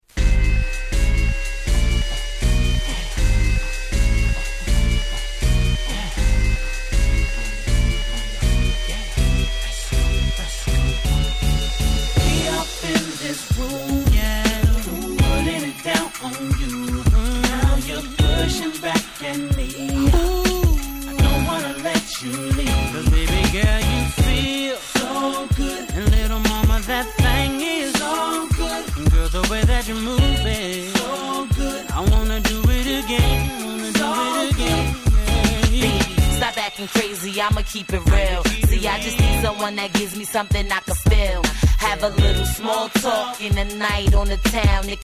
【Media】Vinyl 12'' Single
06' Smash Hit Hip Hop !!